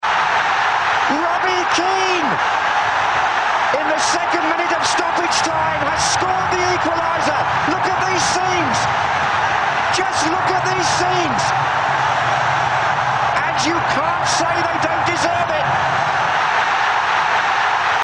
«Look at these scenes! Just look at these scenes!», exclaimed commentator John Motson in 2002.
Robbie Keane’s last minute goal drove the Irish fans wild. Republic of Ireland’s surprising tie against Germany in World Cup 2002 led to commentator John Motson’s famous outburst, allowing the images to speak for themselves.